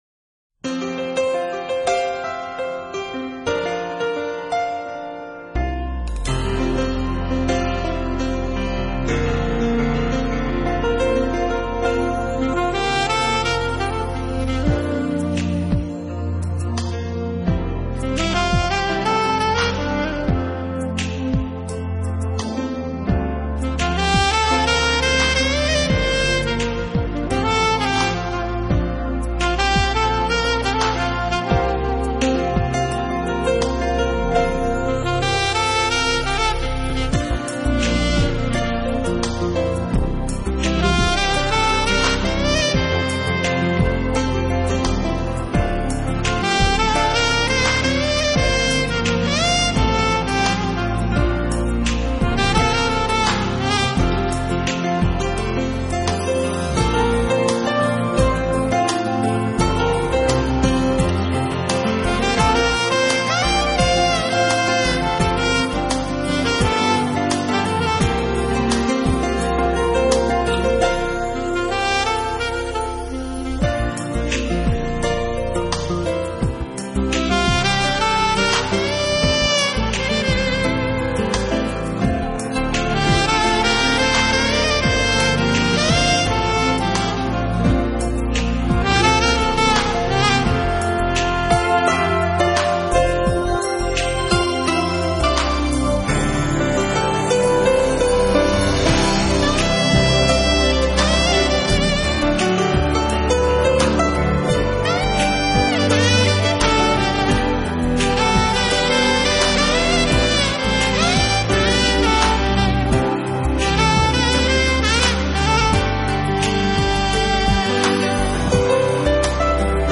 这是一张轻柔的、抒情的NEWAGE音乐
在钢琴与萨克斯悠缓的揉搓交缠中，隐隐为这冬夜的清冽里一拢衣裾。